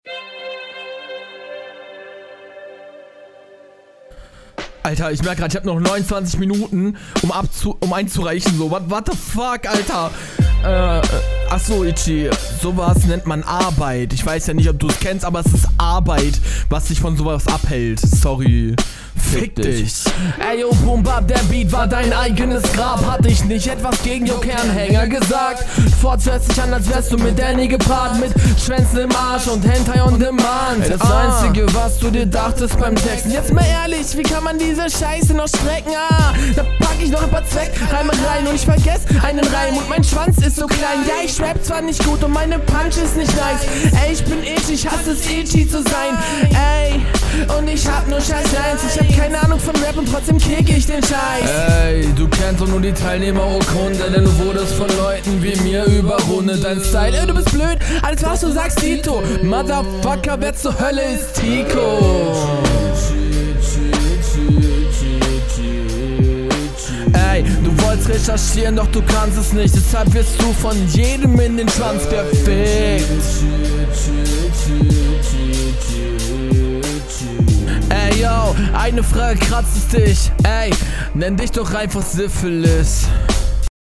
Flow: Flow ist gut und er variiert schön.